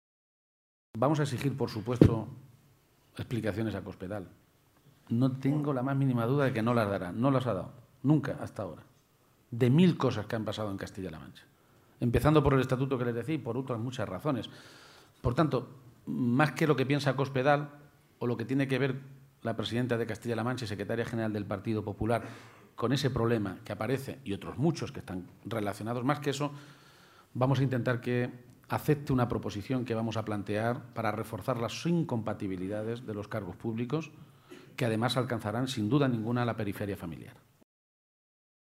García-Page se pronunciaba de esta manera durante el ciclo de conferencias del Foro Nueva Economía, en Madrid, y en el que ha sido presentado por la Presidenta de Andalucía, Susana Díaz, que ha dicho del líder socialista castellano-manchego que es “un buen político, un buen socialista y un buen alcalde”.